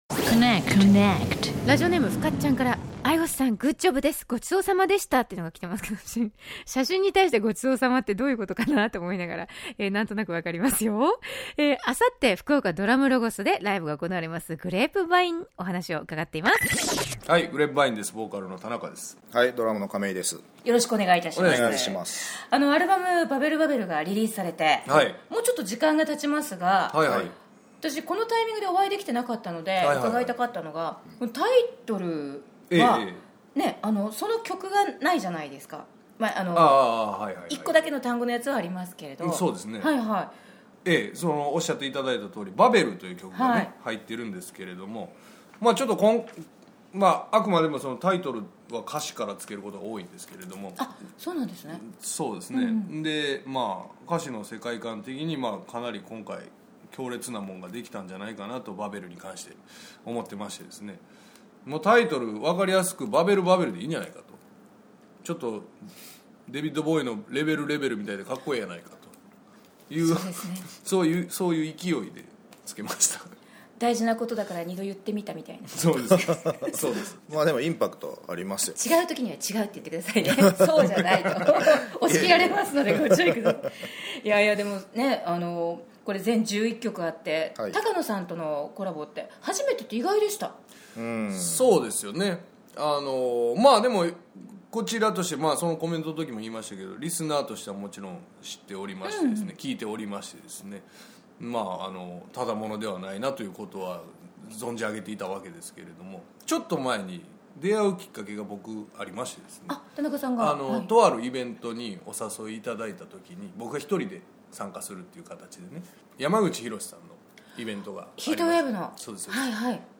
ミッチーのご厚意に甘え岡山公演終了後〜打ち上げ前
お座敷の隅っこに正座してのお座敷インタビュー！